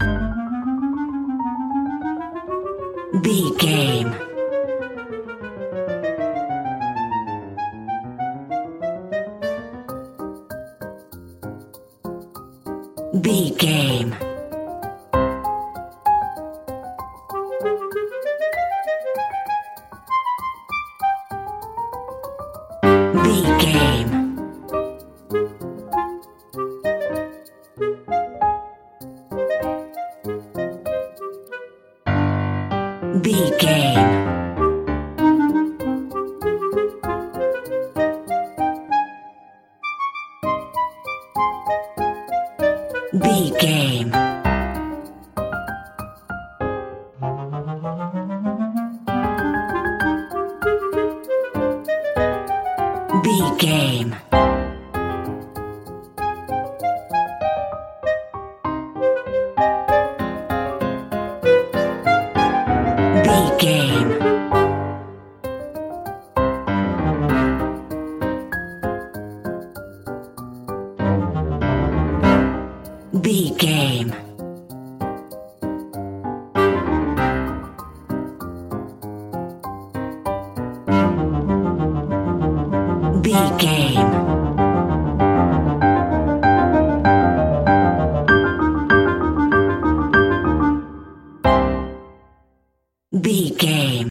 Aeolian/Minor
flute
oboe
strings
goofy
comical
cheerful
perky
Light hearted
quirky